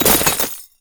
ice_spell_impact_icicle_hits2.wav